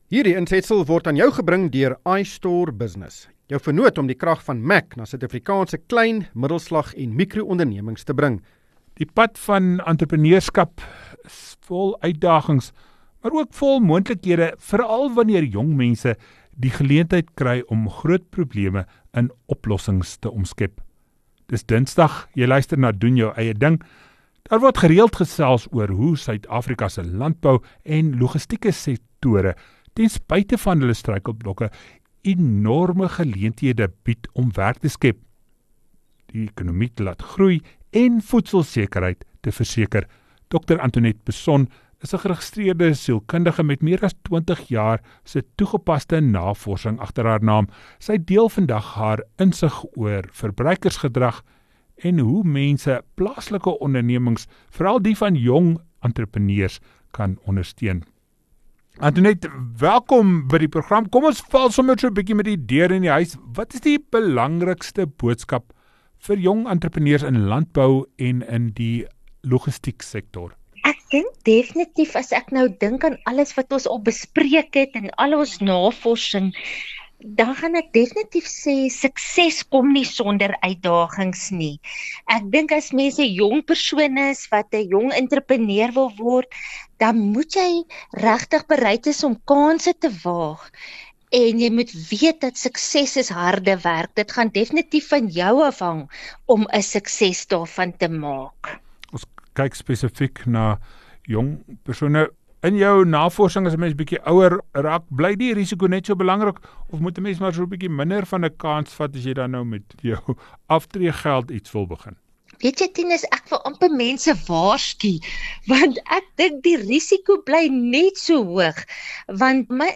Wees deel van die gesprek met beleggingskenners en finansiële gurus in ateljee.